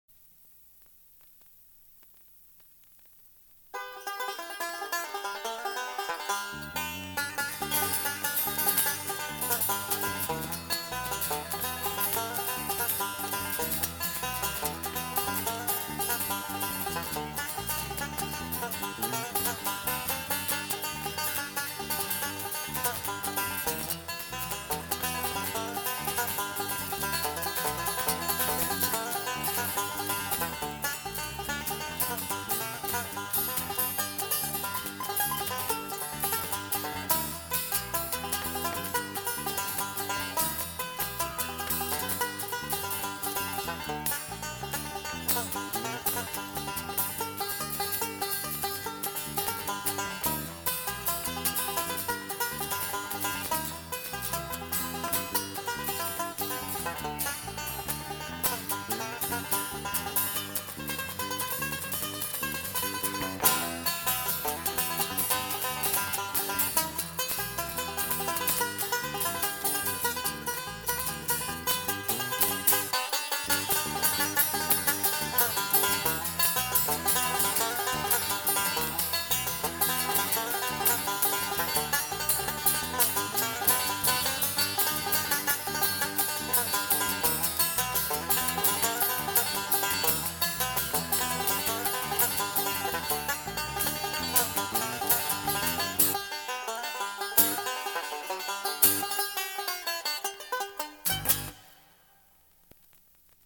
Genre: | Type: Featuring Hall of Famer |Studio Recording